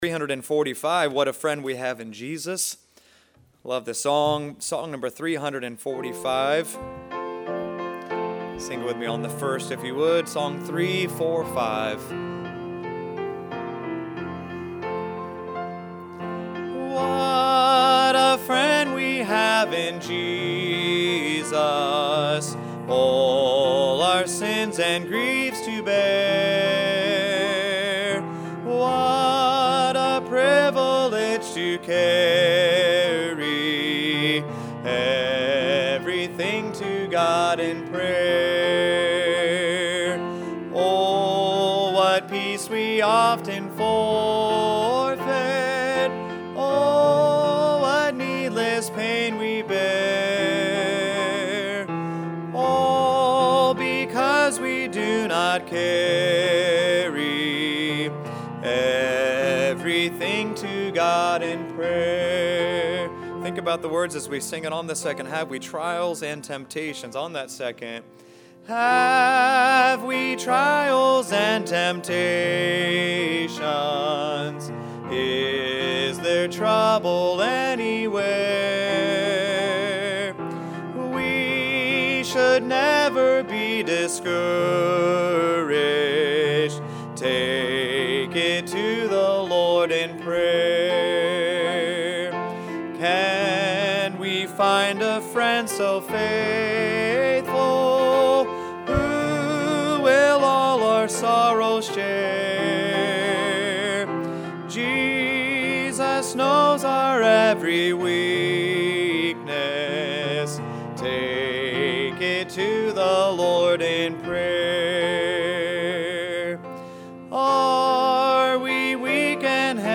Prayer | Sunday School – Shasta Baptist Church